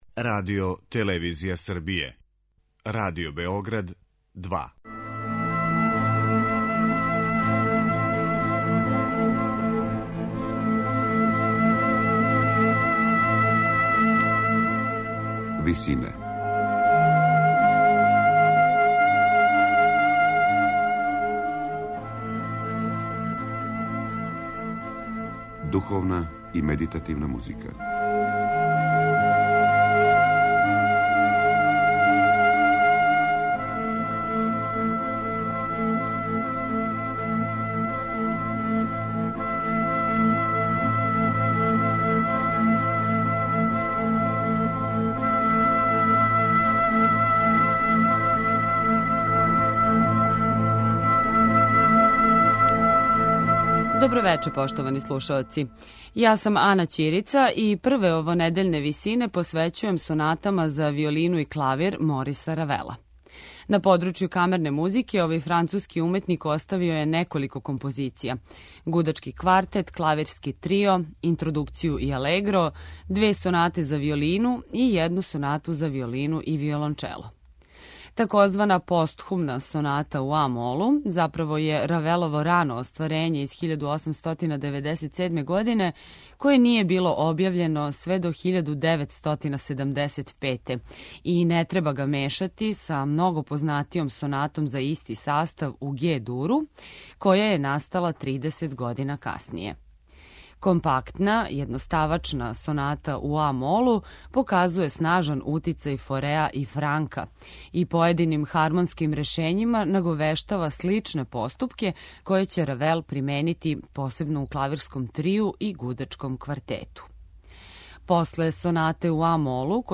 Прве овонедељне Висине посвећујемо сонатама за виолину и клавир Мориса Равела.
На крају програма, у ВИСИНАМА представљамо медитативне и духовне композиције аутора свих конфесија и епоха.